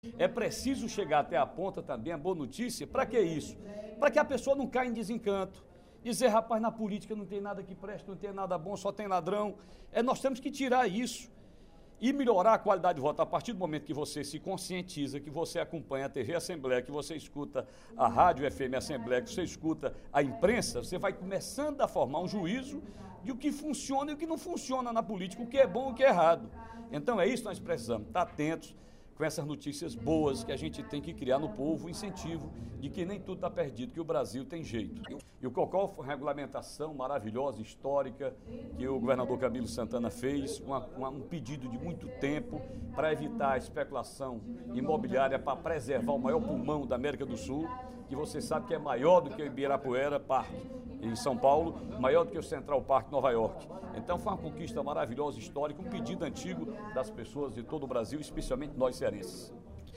O deputado Ferreira Aragão (PDT) enalteceu, nesta terça-feira (06/06), no primeiro expediente da sessão plenária da Assembleia Legislativa, a aprovação de matérias que beneficiam delegados e defensores públicos do Estado.